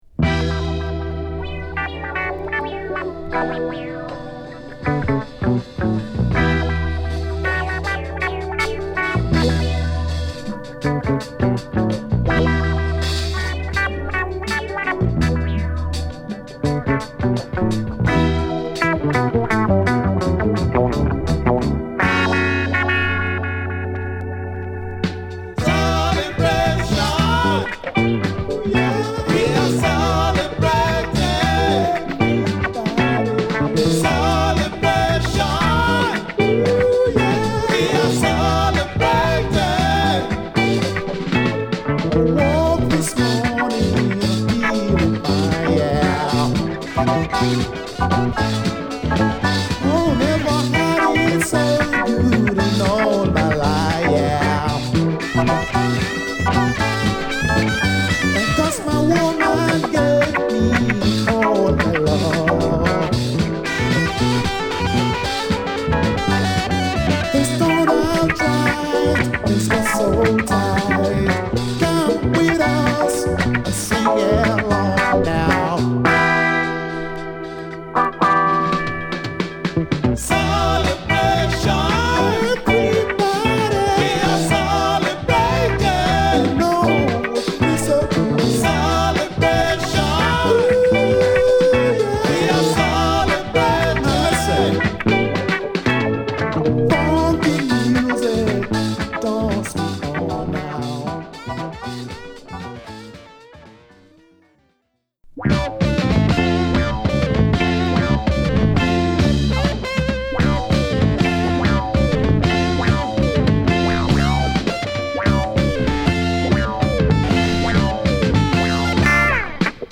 強力なアフロファンク／レアグルーヴを満載！